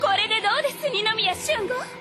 本編から拾った「や」の音声を比べてみます。